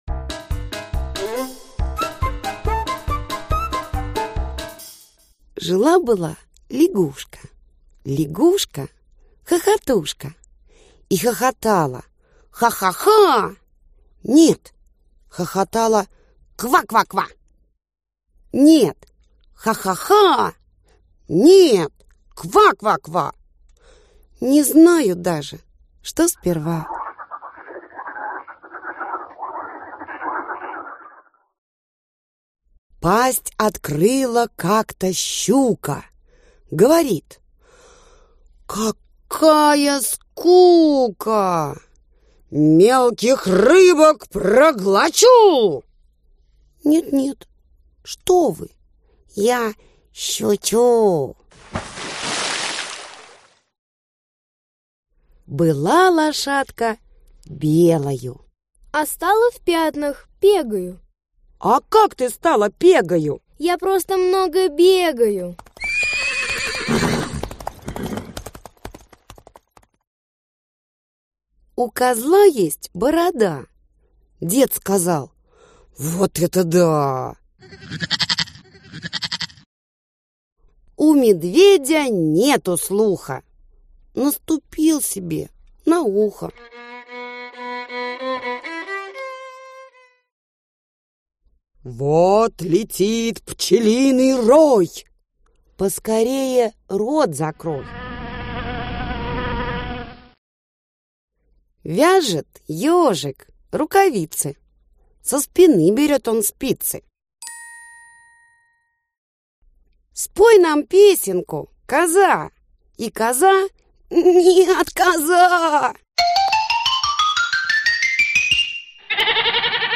Аудиокнига 200 стишков-малюток | Библиотека аудиокниг